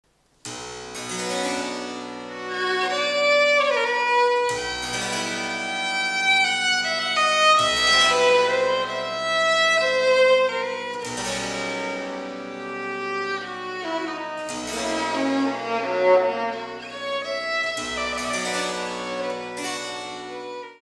This year, I also participated in the Bachfest as performer in one of the free concerts in the Sommersaal on 6 May at 3 pm, playing
Baroque Violin